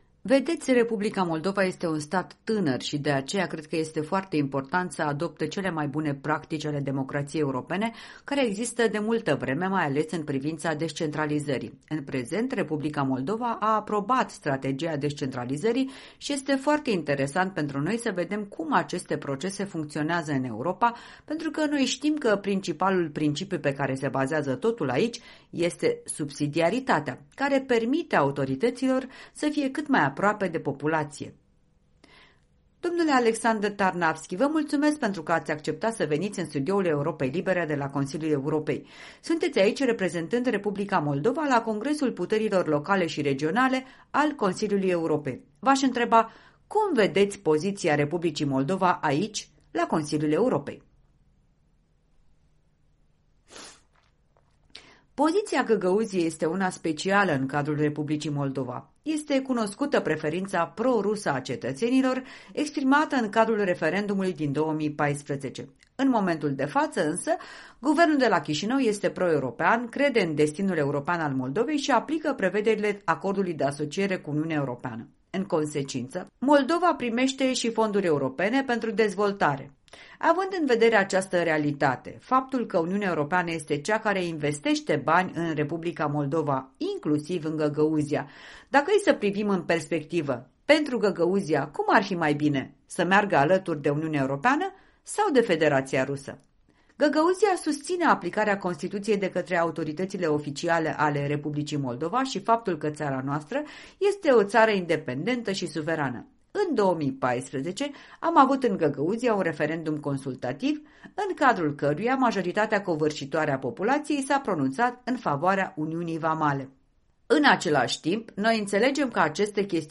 Un interviu, la Strasbourg, cu vicepreședintele Adunării Populare a UTA Găgăuzia.